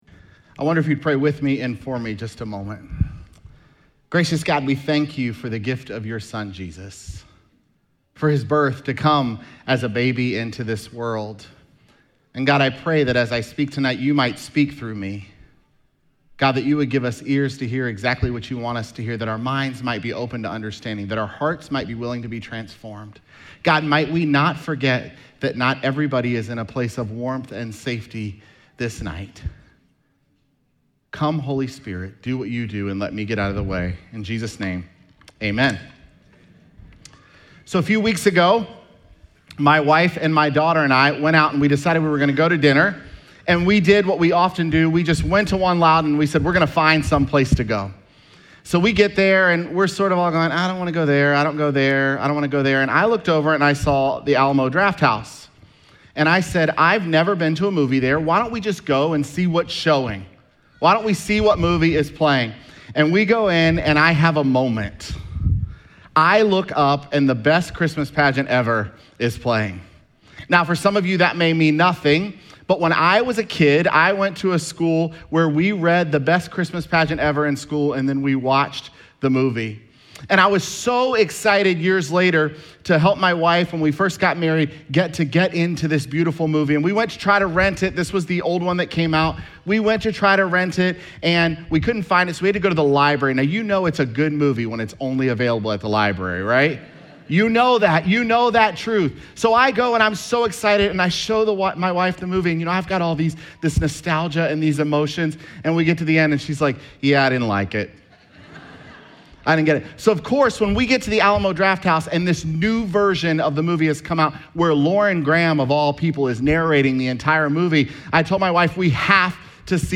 Sermons
Dec24SermonPodcast.mp3